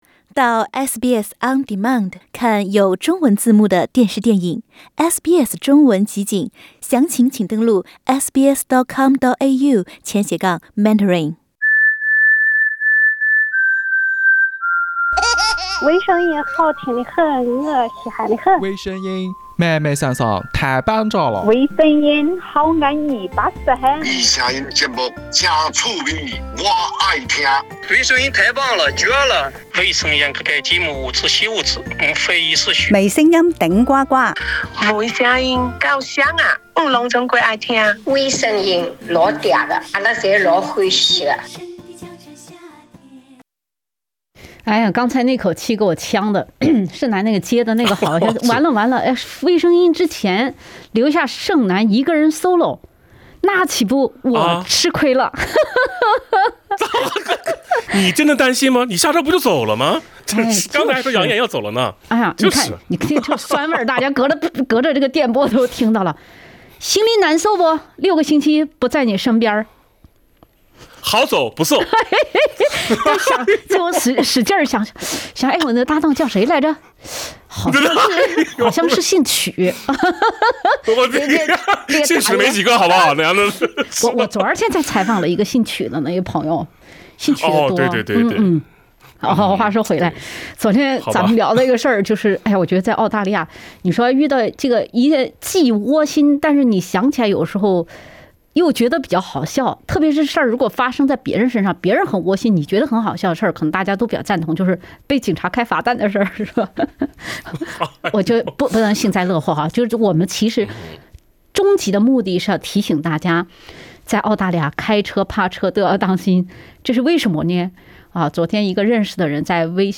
来自悉尼民间高人的“新疆欢乐民谣”